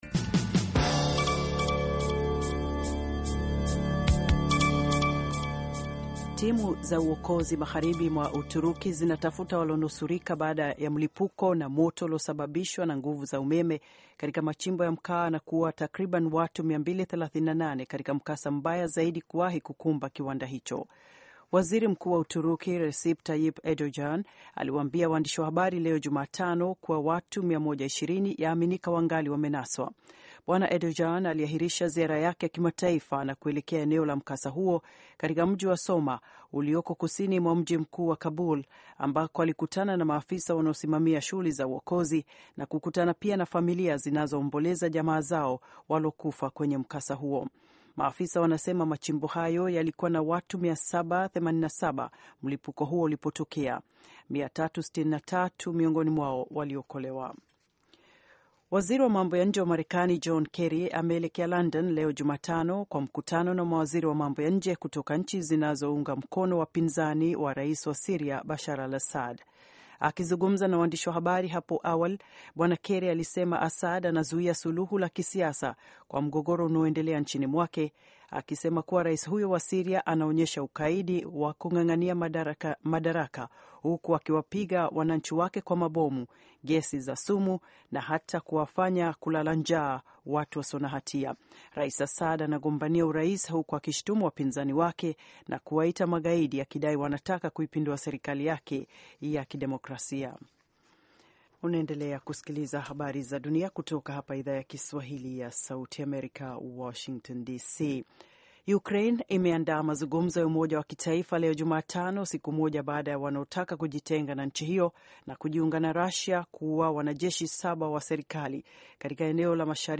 Taarifa ya Habari VOA Swahili - 5:39